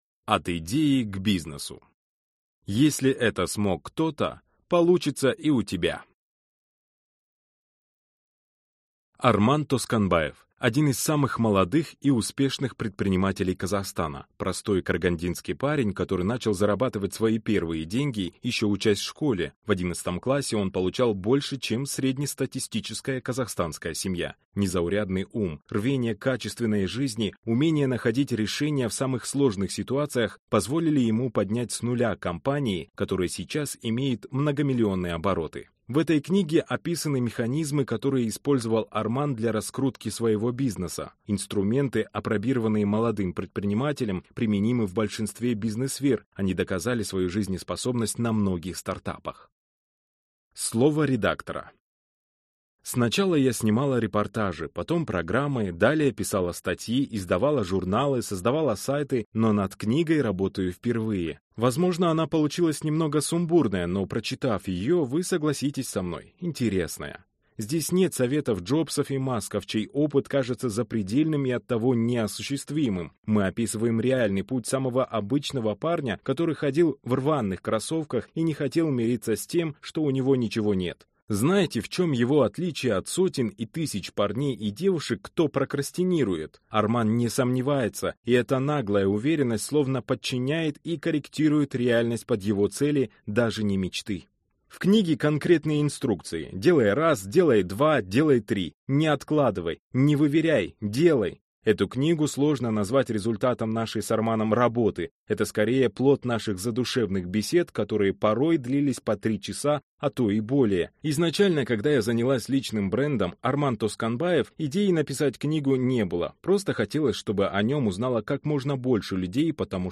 Аудиокнига От идеи к бизнесу. Если это смог кто-то, получится и у меня | Библиотека аудиокниг